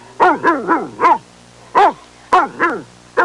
Bloodhound Sound Effect
Download a high-quality bloodhound sound effect.
bloodhound-1.mp3